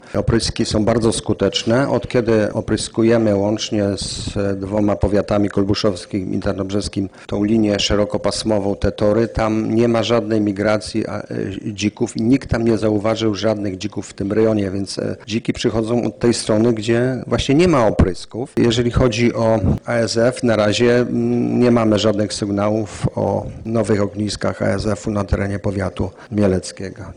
Mówi starosta mielecki Stanisław Lonczak.